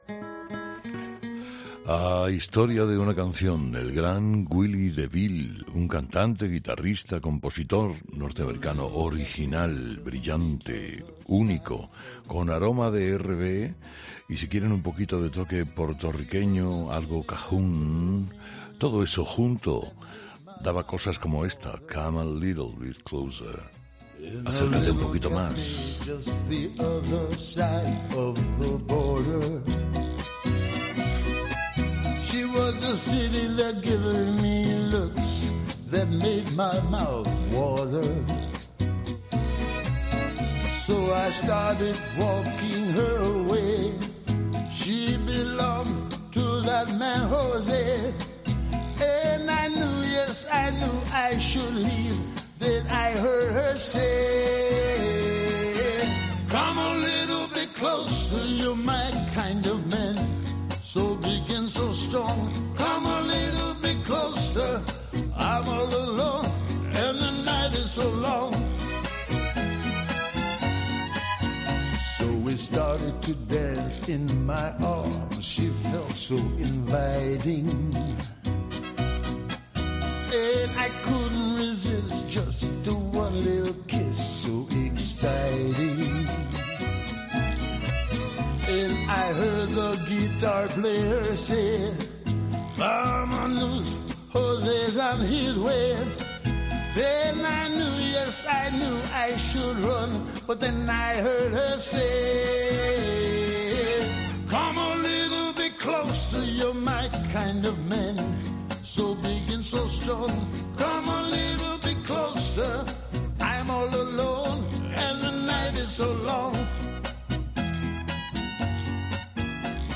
un cantante, guitarrista, compositor norteamericano
con aroma R&B y si quieren un poquito de toque puertoriqueño